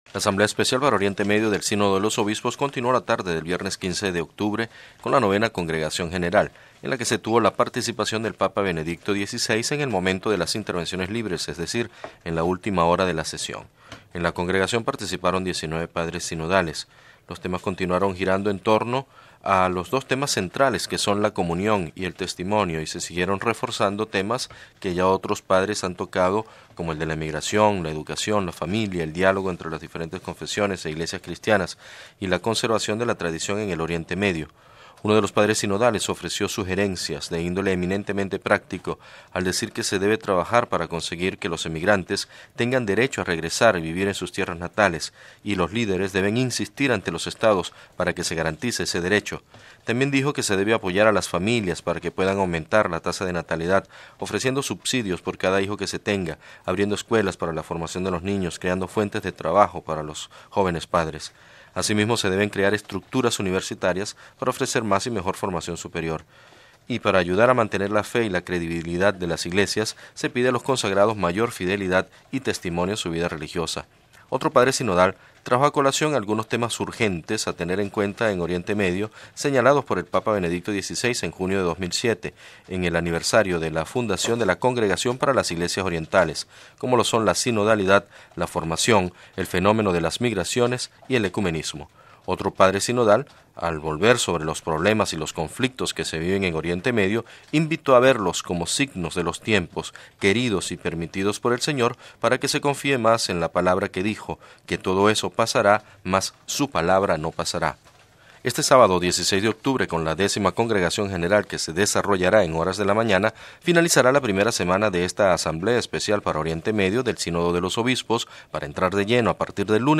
Desde el Aula Nueva del Sínodo en el Vaticano